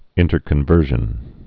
(ĭntər-kən-vûrzhən)